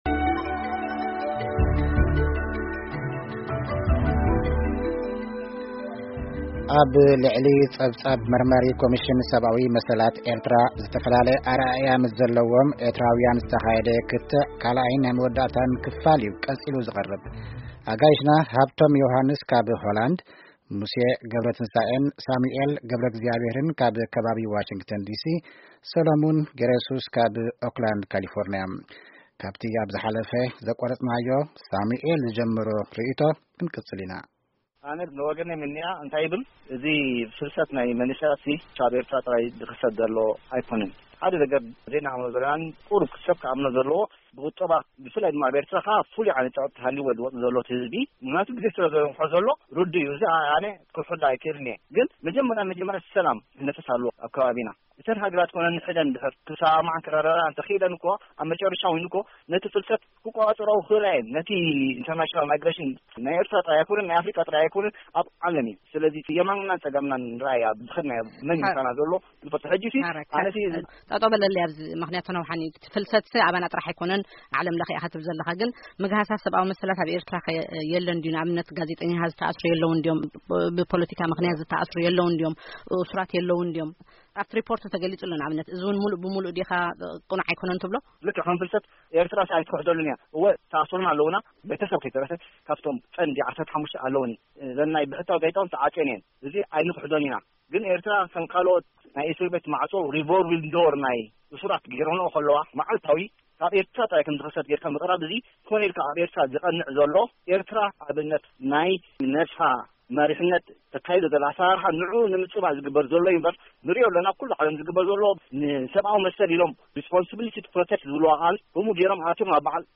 ነዚ ጸብጻብ እታ መርማሪት ኮሚተ ብዝምልከት ክትዕ ሓሙሽተ ዝተፈላለዩ አራአእያ ዘለዎም ኤርትራዊያን ካልአይ ክፋል አብዚ ምስማዕ...